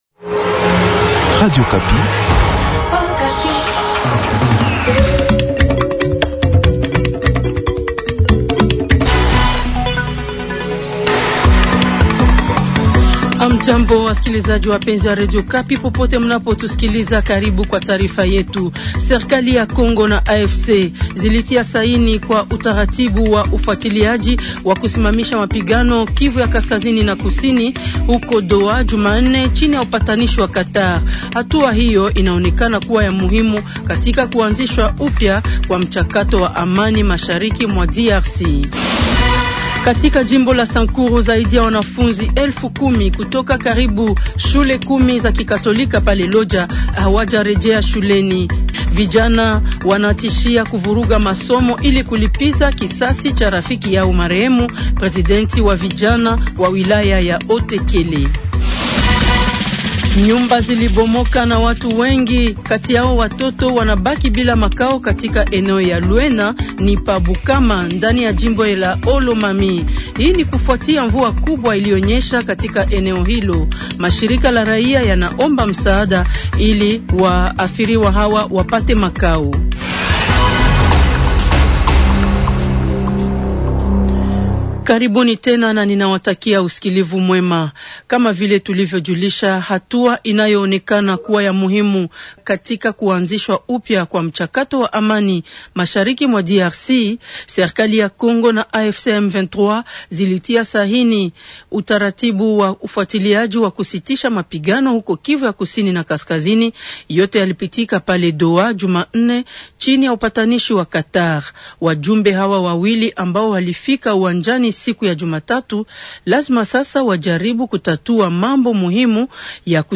journal soir
Journal swahili SOIR du 14 octobre 2025.